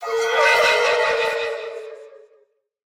Minecraft Version Minecraft Version 1.21.5 Latest Release | Latest Snapshot 1.21.5 / assets / minecraft / sounds / mob / allay / idle_without_item2.ogg Compare With Compare With Latest Release | Latest Snapshot